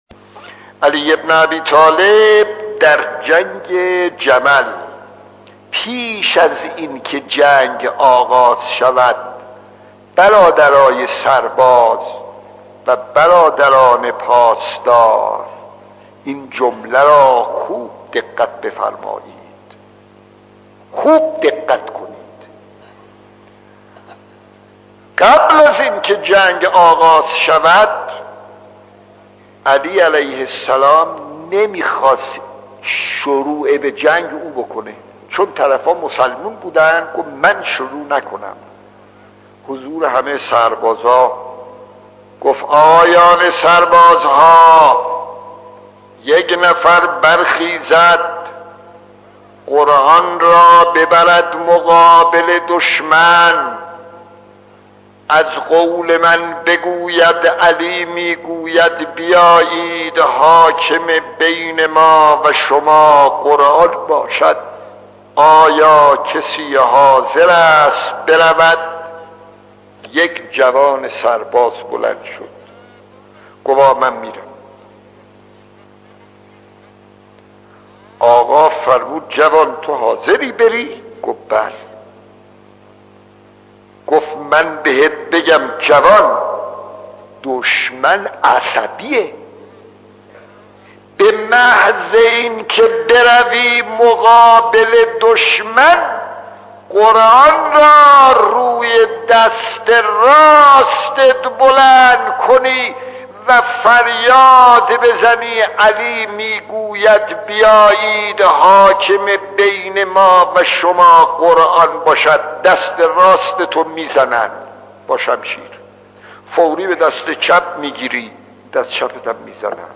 داستان 10 : امام علی در جنگ جمل خطیب: استاد فلسفی مدت زمان: 00:04:24